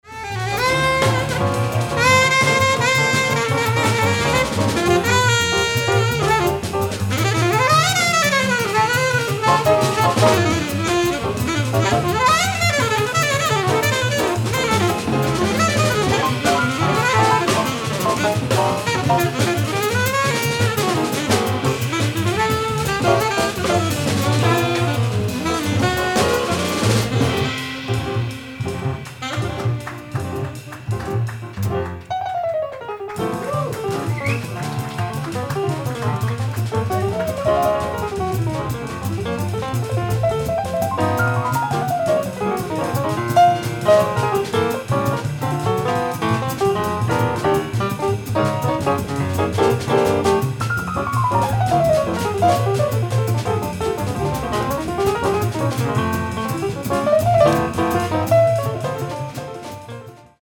piano
acoustic bass
drums
modern mainstream jazz